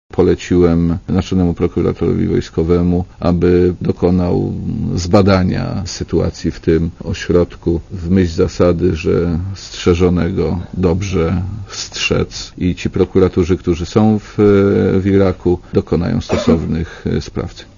zapowiedział w Brukseli minister obrony, Jerzy Szmajdziński.
Posłuchaj komentarza Jerzego Szmajdzińskiego